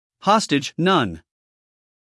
英音/ ˈhɒstɪdʒ / 美音/ ˈhɑːstɪdʒ /